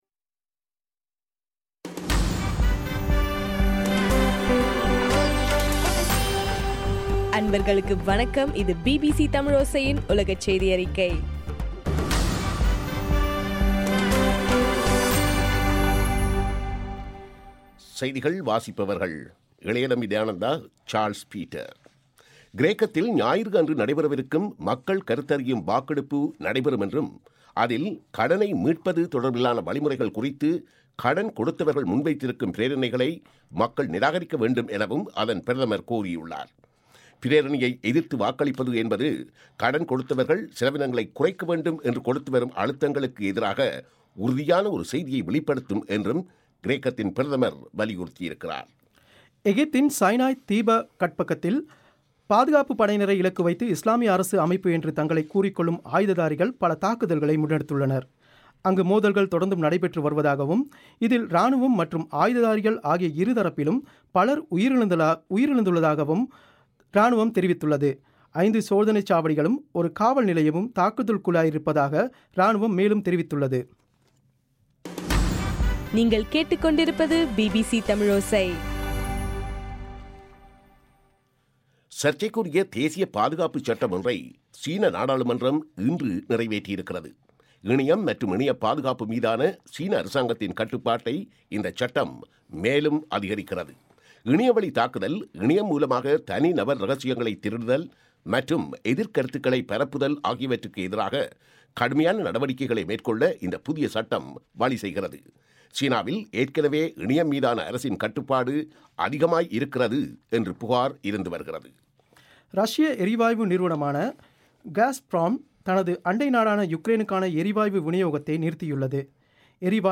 ஜூலை 1 பிபிசியின் உலகச் செய்திகள்